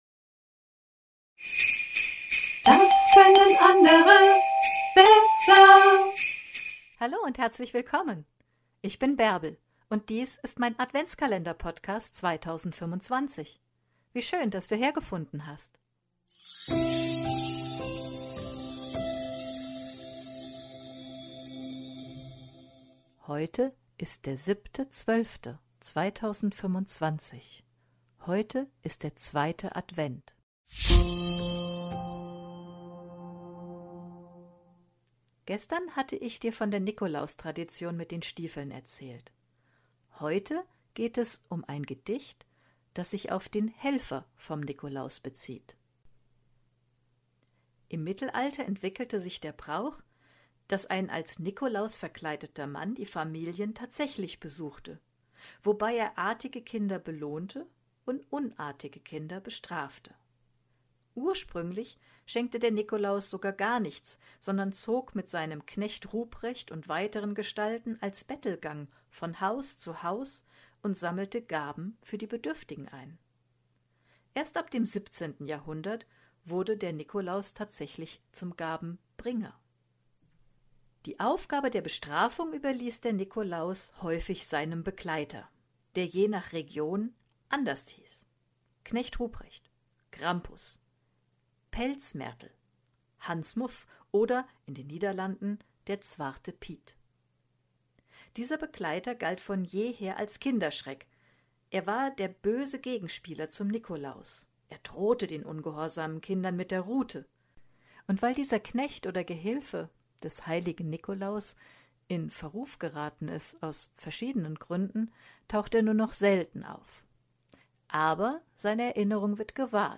trage ich ein bekanntes Gedicht über seinen Helfer Knecht Ruprecht